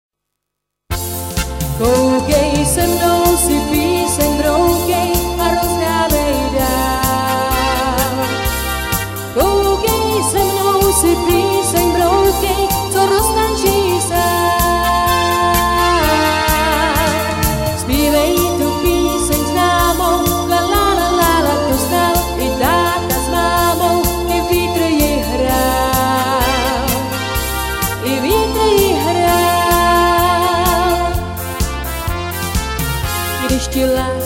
Rubrika: Pop, rock, beat
Hudební podklady AUDIO a VIDEO